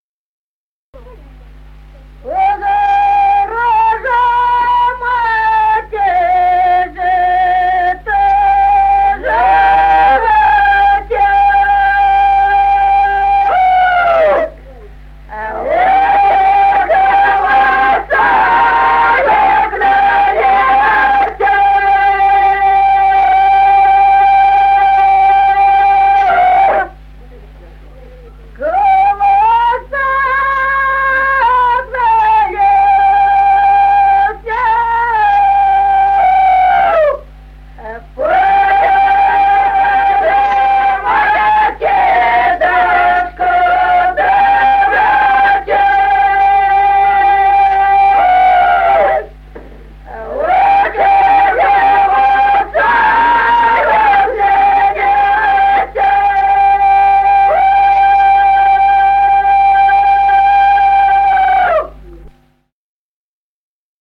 Песни села Остроглядово. Пора же, мати, жито жати (зажиночная).